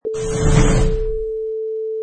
Sound bytes: Dresser Drawer Shut 3
Dresser drawer being shut
Product Info: 48k 24bit Stereo
Category: Household / Bedroom - Dressers
Try preview above (pink tone added for copyright).
Dresser_Drawer_Shut_3.mp3